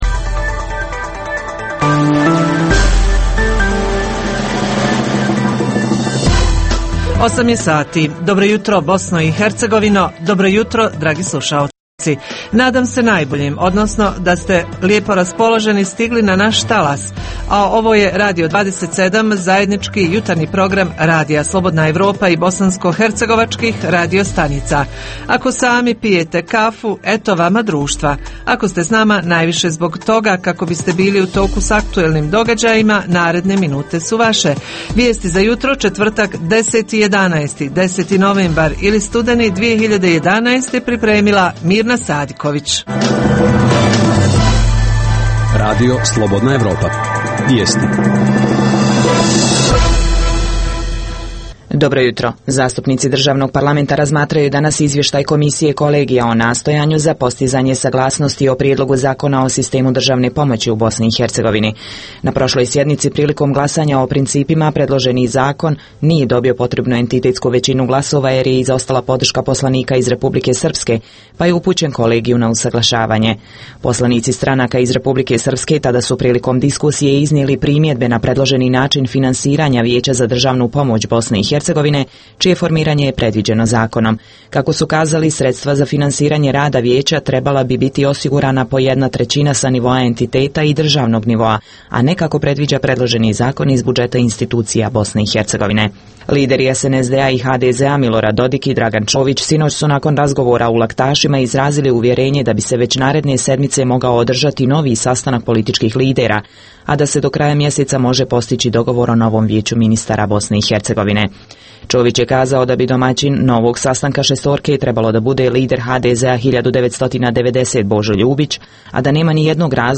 Položaj poljoprivrede i poljoprivrednika - zbog čega su nezadovoljni, šta su vlade obećavale, a šta ispunile? Reporteri iz cijele BiH javljaju o najaktuelnijim događajima u njihovim sredinama.
Redovni sadržaji jutarnjeg programa za BiH su i vijesti i muzika.